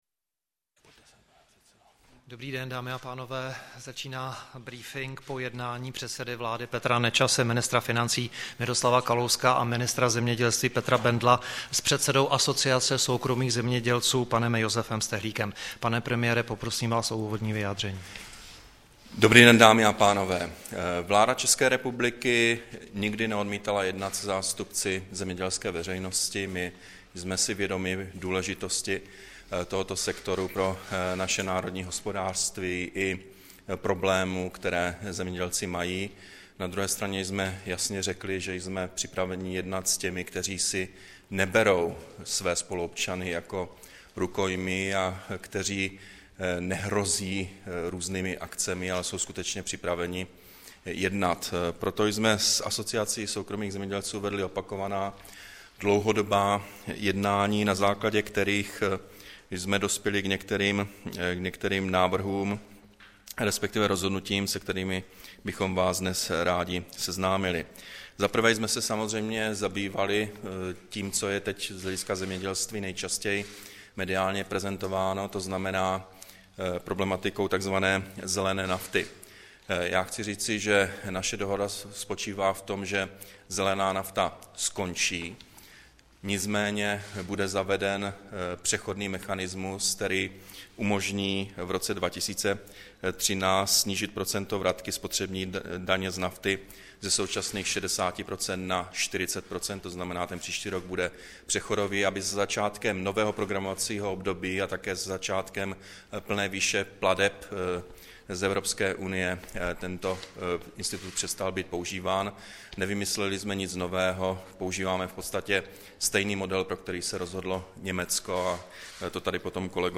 Tiskový brífink premiéra Petra Nečase, ministrů Petra Bendla a Miroslava Kalouska